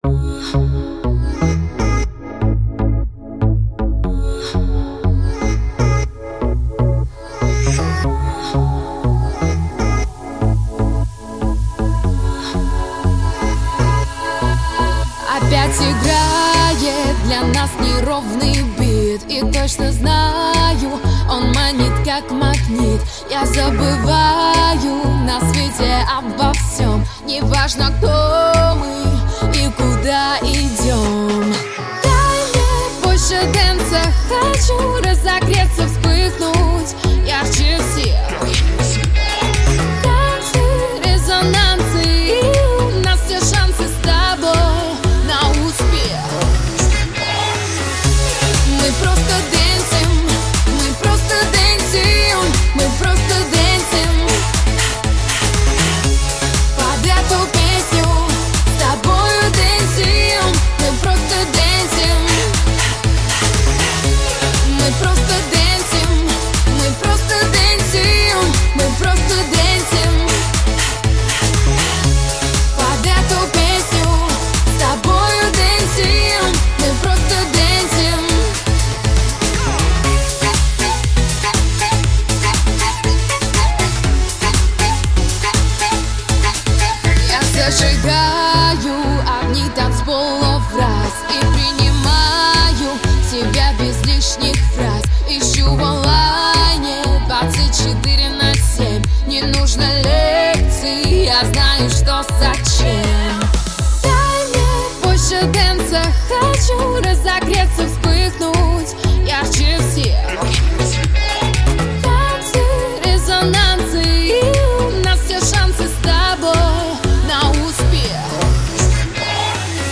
ГлавнаяПесниПесни и музыка для танцев